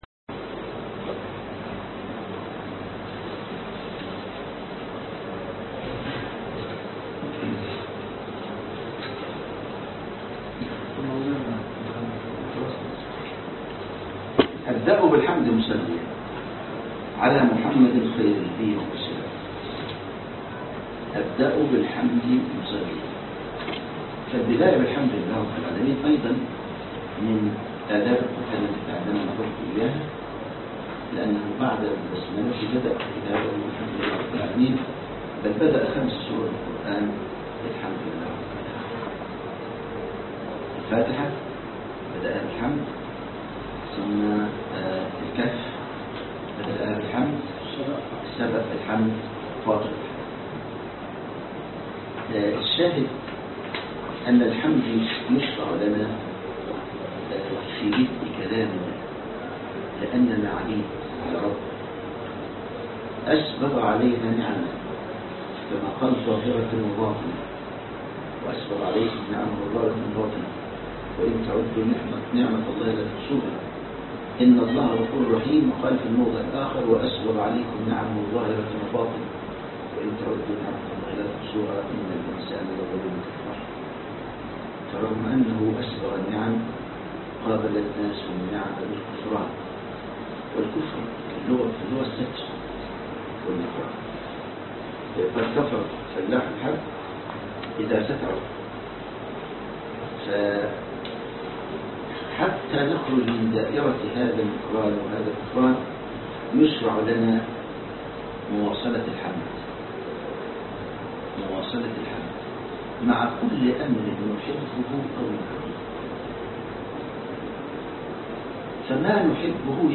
الدرس1 (شرح متن البيقونية